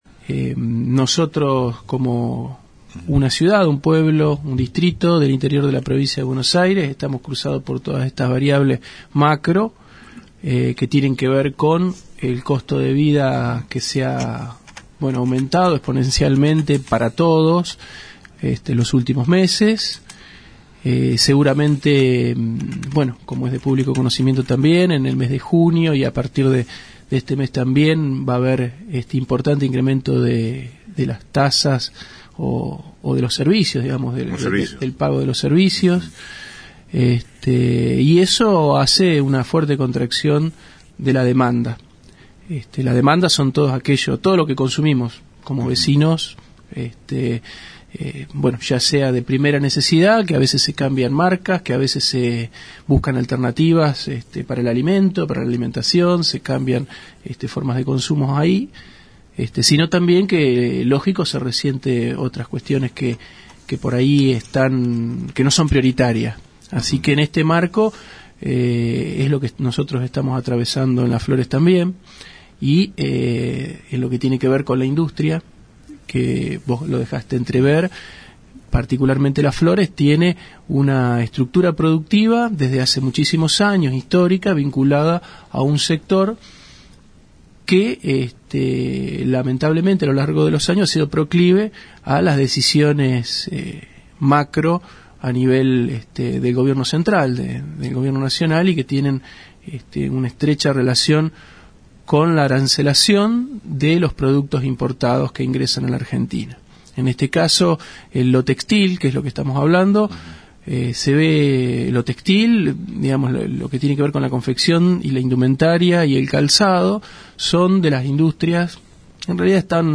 En una amplia entrevista, y ante la transición económica que está viviendo el país, estuvo en “El Periodístico” Cristian Chiodini quien maneja la cartera de Producción, Turismo y Ambiente. También se refirió a nuestro turismo, y al ambiente tocando la planta de reciclado de residuos.
AUDIO COMPLETO DE LA ENTREVISTA A CRISTIAN CHIODINI